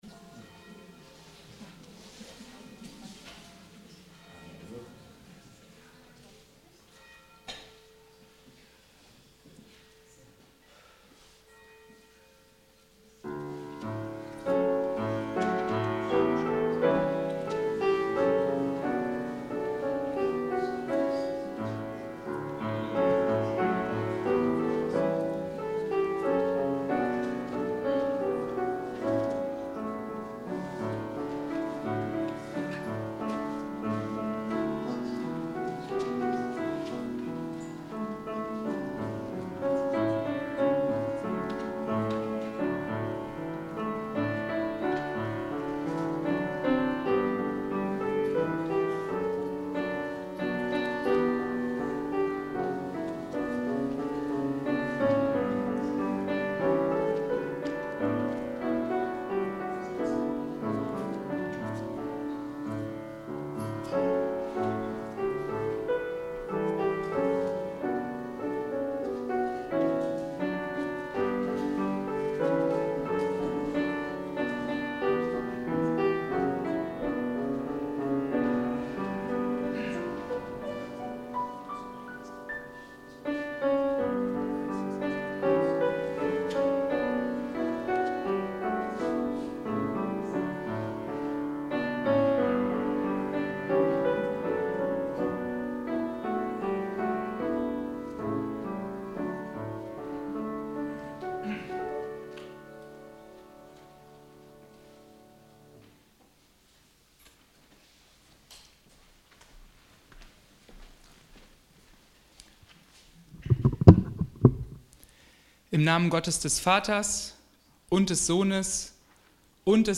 Gottesdienst - 22.03.2026 ~ Peter und Paul Gottesdienst-Podcast Podcast